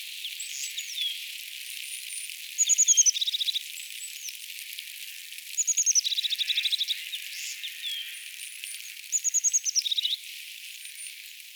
onko tämä töyhtötiaisen laulu
vaiko hömötiaisen laulua?
onko_tuo_homotiaisen_laulua_vaiko_toyhtotiaisen.mp3